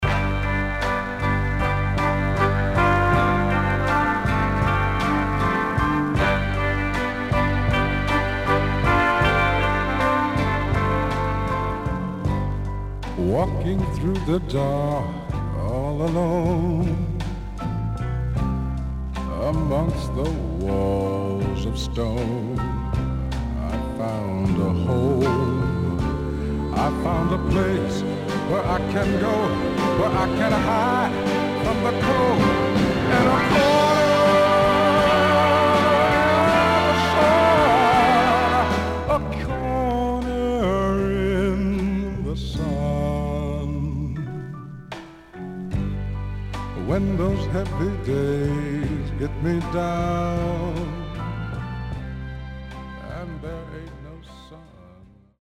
HOME > SOUL / OTHERS
SIDE A:少しノイズ入りますが良好です。